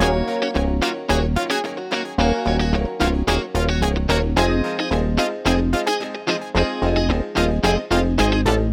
03 Backing PT2.wav